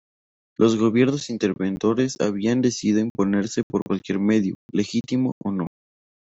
Pronounced as (IPA) /ˈmedjo/